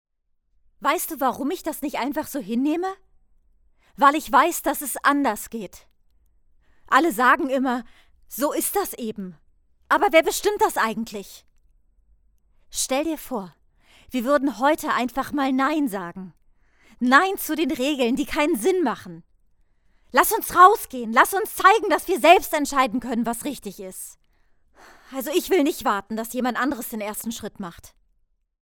Studiosprechen
Sprachdemos